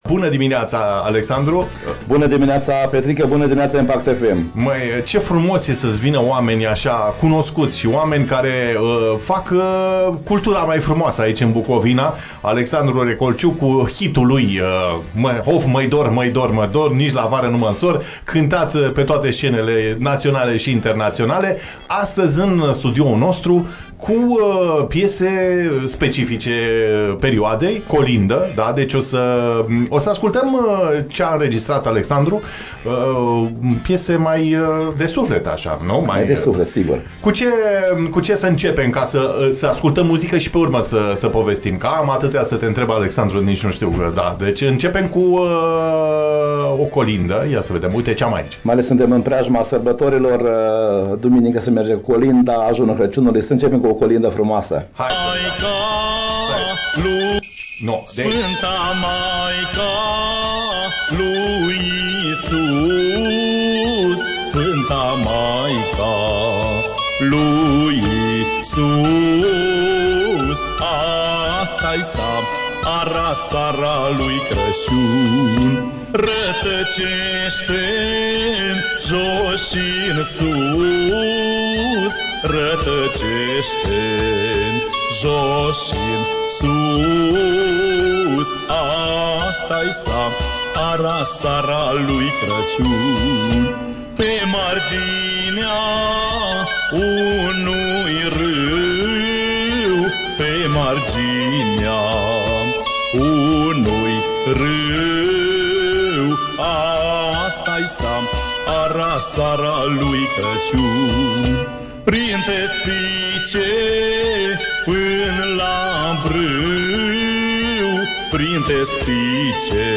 Urmarea? Lansarea unei colinde, bună dispoziție și o piesă cântată live în studio!